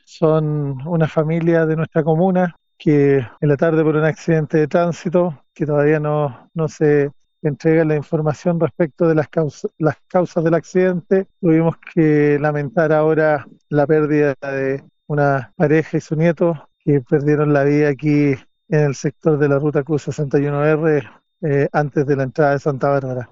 Según señaló a Radio Bío Bío el alcalde de Santa Bárbara, Cristián Osses, los fallecidos corresponden a una pareja de adultos junto a su nieto, quienes hacía poco tiempo se habían ido a vivir al sector rural Los Naranjos.
accidente-santa-barbara-alcalde.mp3